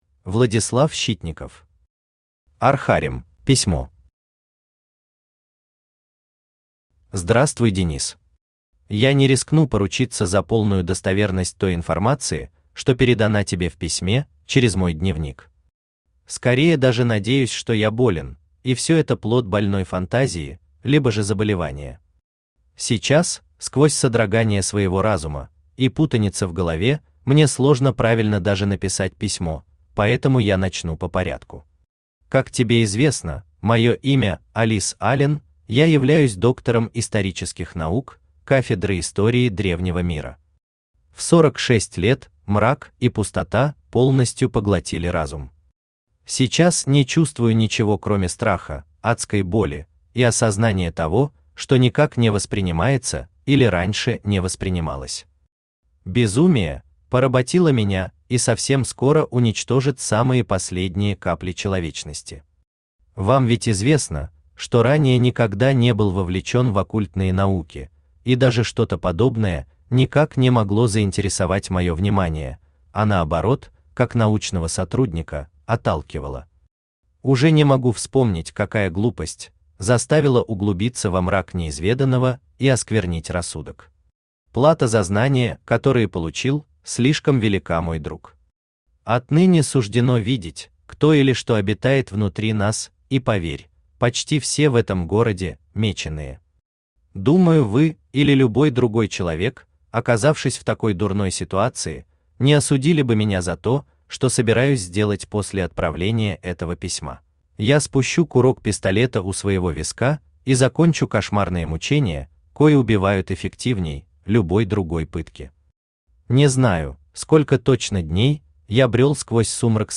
Aудиокнига Архарим Автор Владислав Щитников Читает аудиокнигу Авточтец ЛитРес.